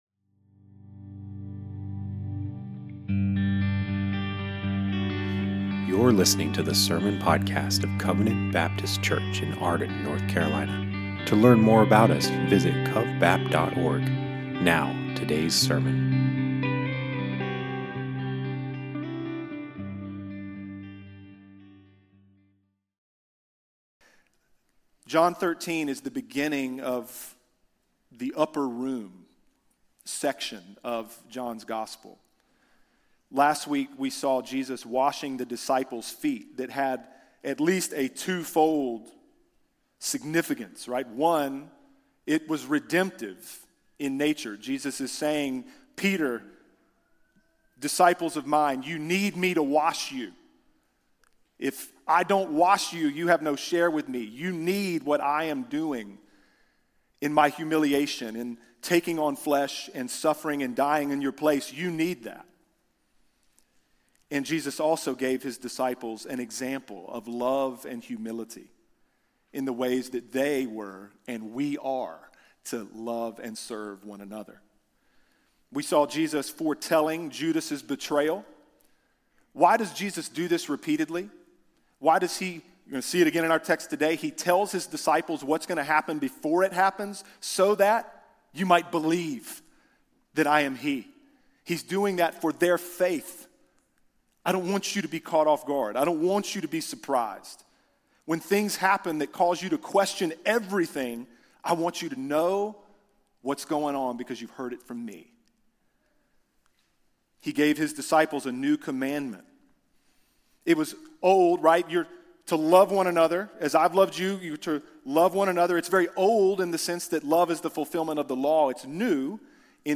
Sermons from the weekly Sunday morning gathering at Covenant Baptist Church in Arden, NC